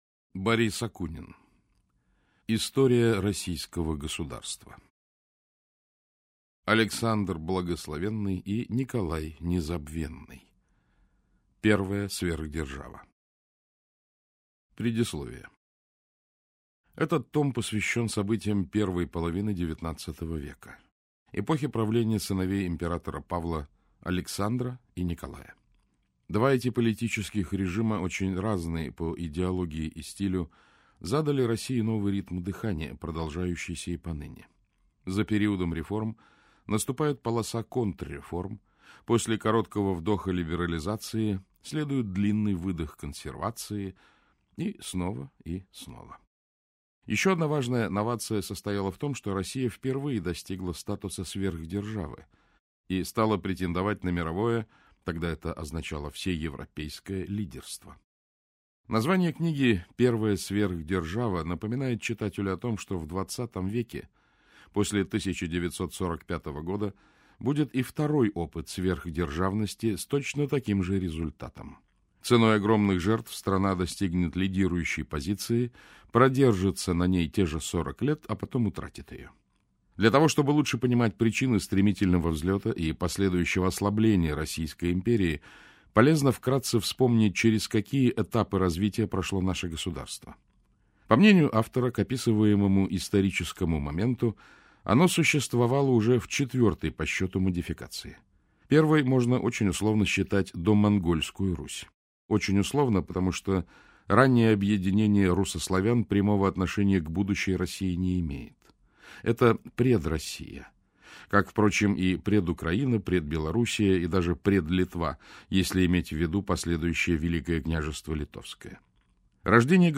Аудиокнига Первая сверхдержава.